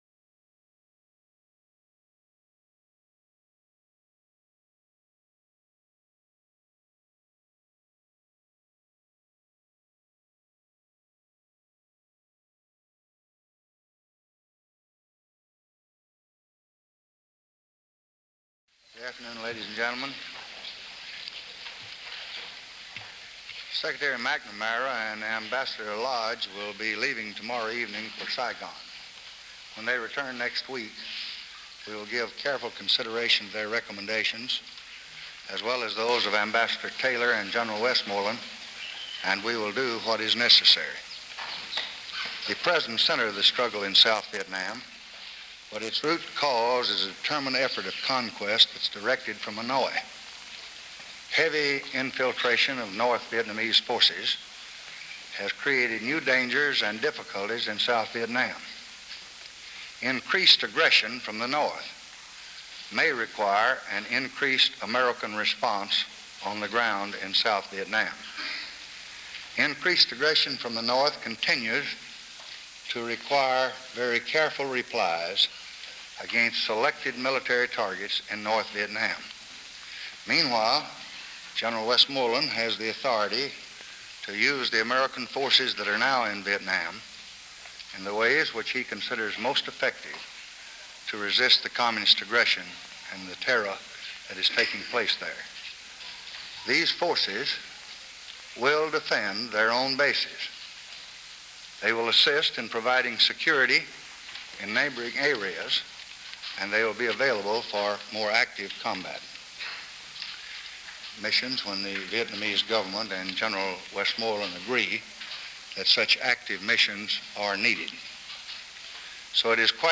July 13, 1965: Press Conference in the East Room | Miller Center
Presidential Speeches | Lyndon B. Johnson Presidency